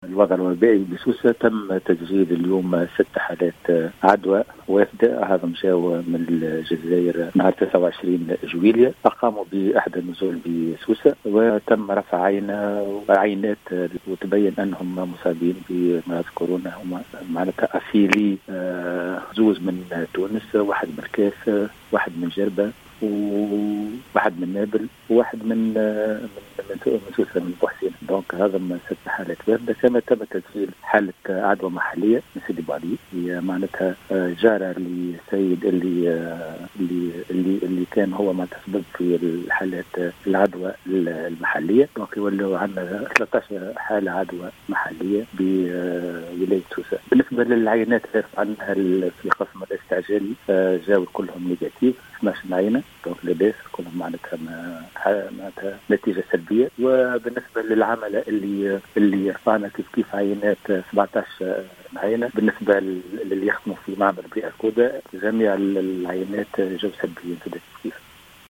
أكد المدير الجهوي للصحة بسوسة محمد الميزوني الغضباني في تصريح للجوهرة اف ام مساء...